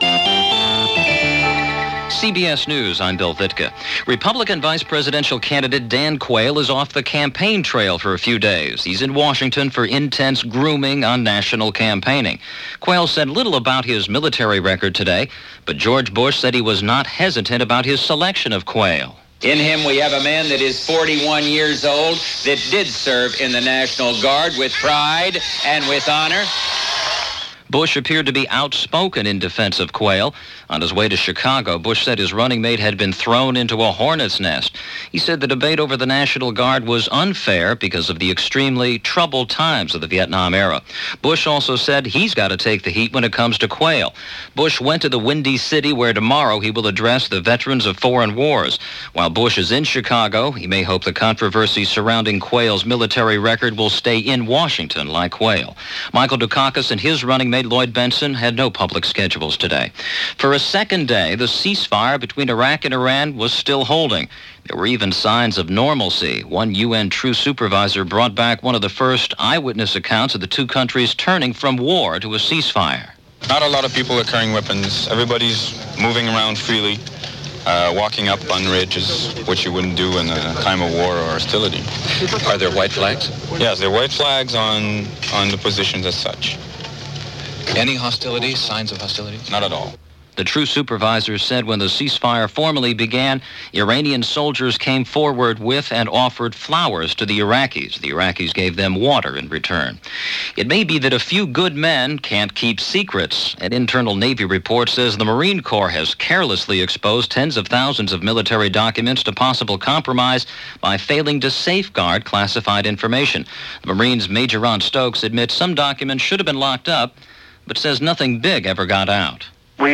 And that’s a little of what happened, this August 21, 1988 as reported by CBS Radio News On The Hour.